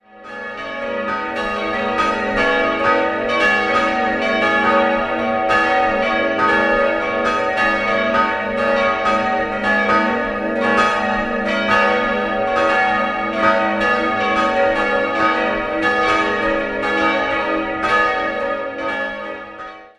4-stimmiges Geläut: as'-b'-des''-es'' Die beiden größeren Glocken wurden 1988 von Bachert, die dritte 1950 von Johann Hahn gegossen.